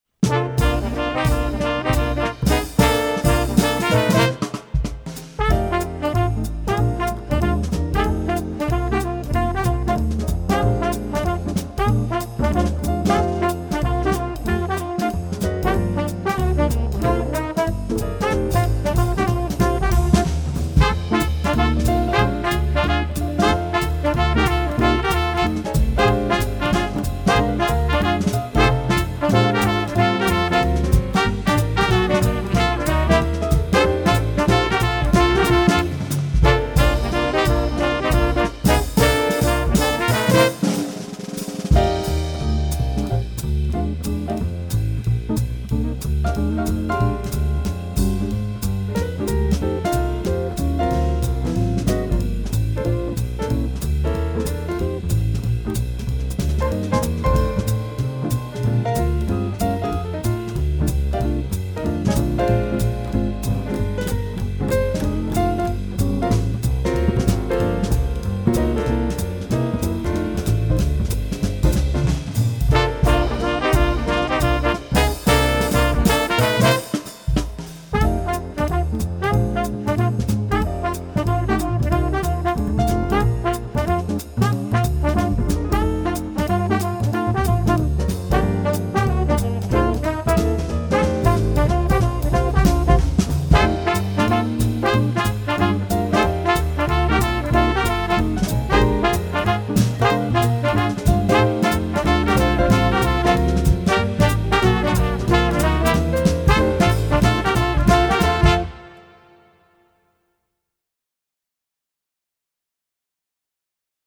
Gattung: Combo
Besetzung: Ensemble gemischt